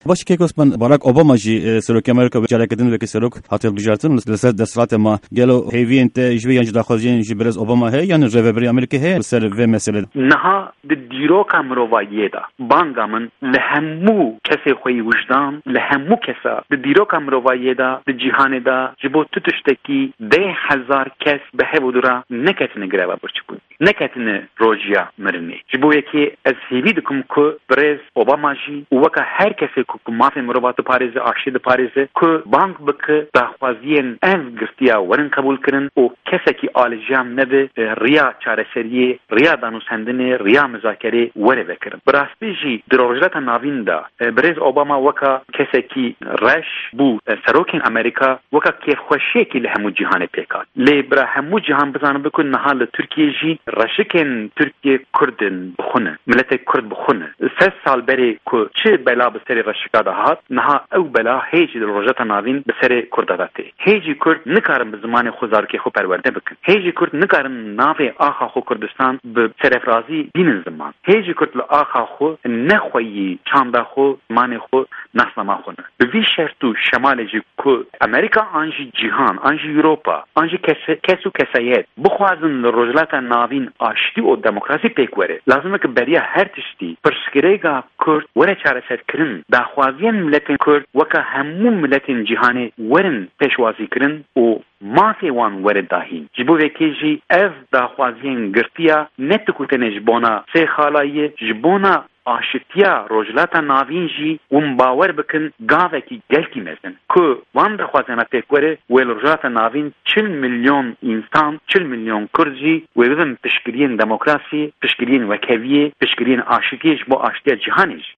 Şaredarê Bajarê Mezin yê Dîyarbekirê Osman Baydemîr, di hevpeyvîna taybet ya Pişka Kurdî ya Dengê Amerîka de bang li Serokê Amerîka Barack Obama dike ku di dawîanîna greva birçîbûna de roleke bi bandor bilîze.